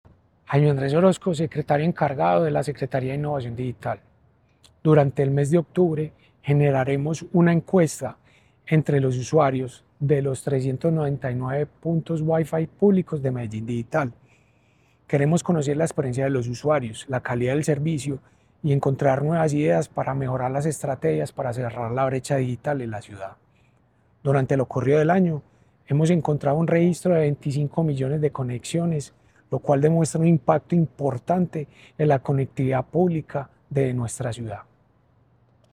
puntosconexioninternes_subsecretario.mp3